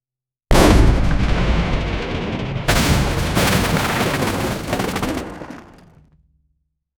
helicopter hovering, propellers chopping, giant stone whooshes down, thunderous impact on cruise ship, metal bending, containers smashing, ocean splashing, debris flying, VFX disaster, no humans. 0:07
helicopter-hovering-prope-lcgy64mc.wav